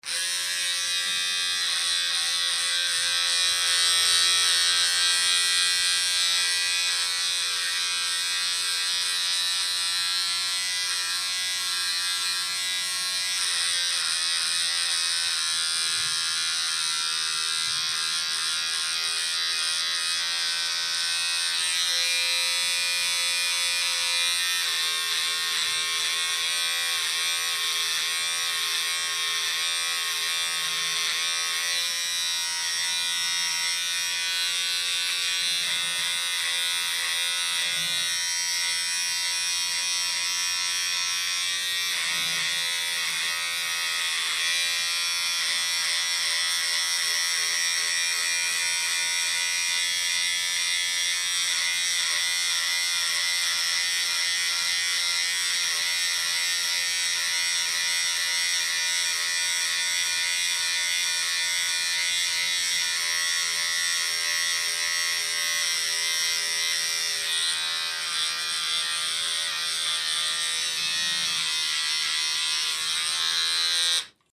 Звуки бритвы